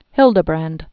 (hĭldə-brănd)